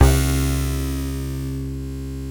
BUZZBASSC2-L.wav